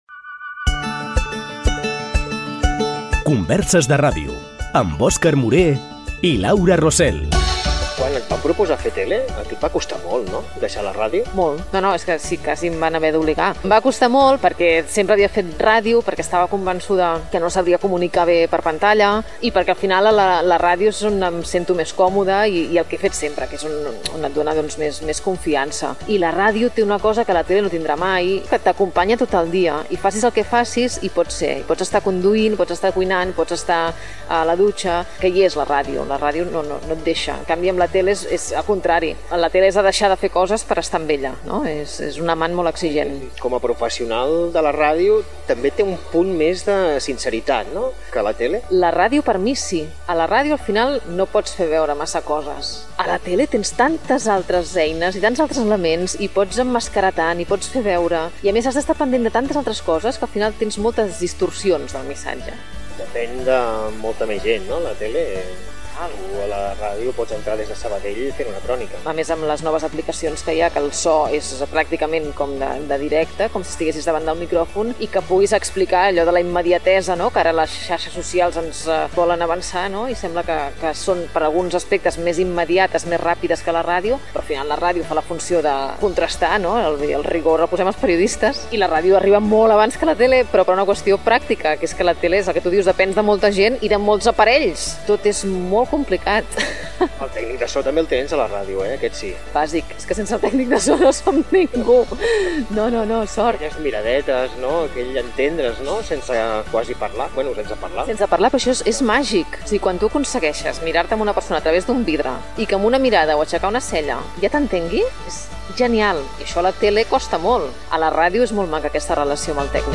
Careta del programa i diàleg entre els dos participants sobre les diferències entre el treball a la ràdio i la televisió.
Divulgació